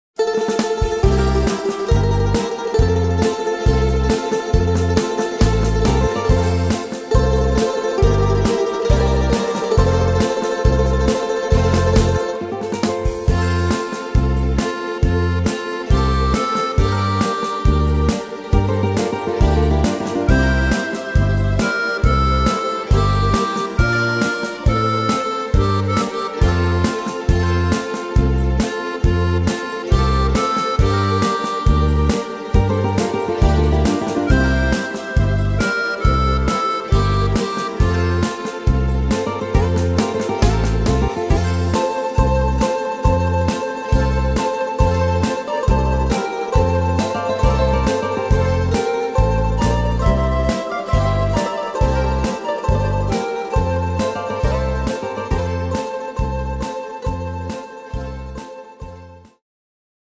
Tempo: 137 / Tonart: F-Dur